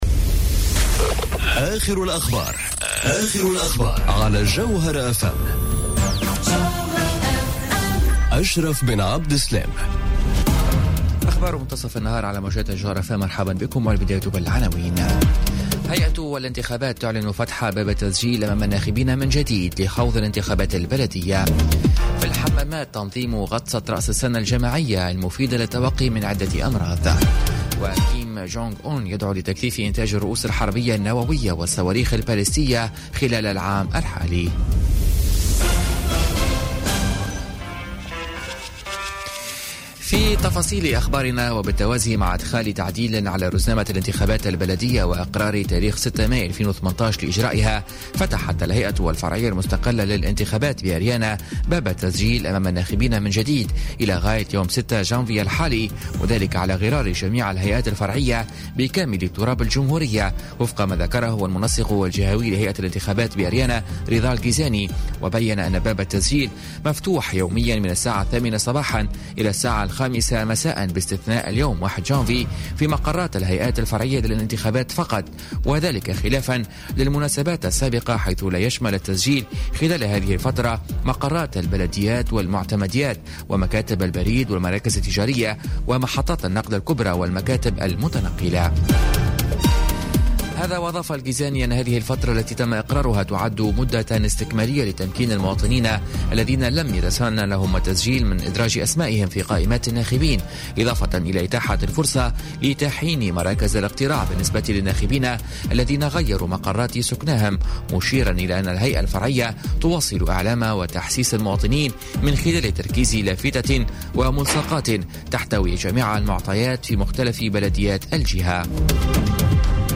نشرة أخبار منتصف النهار ليوم الإثنين 01 جانفي 2018